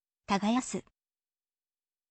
tagayasu